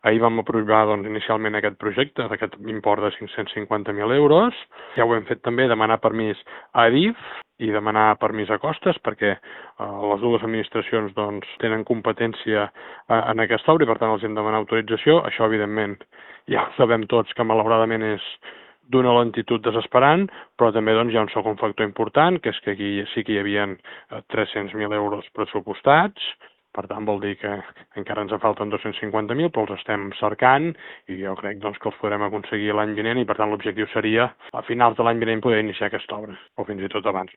En paral·lel, l’Ajuntament avança en el projecte per fer accessible el pas subterrani d’Àngel Guimerà. L’alcalde ha explicat que s’acaba d’aprovar inicialment i que el cost de l’actuació s’enfila fins als 550.000 euros.